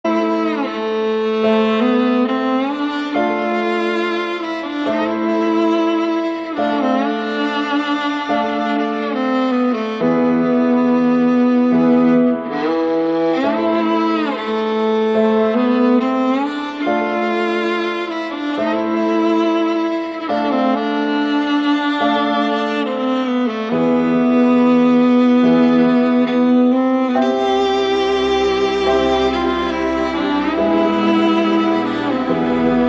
• Качество: 128, Stereo
грустные
мелодичные
спокойные
без слов
инструментальные
романтичные
из сериалов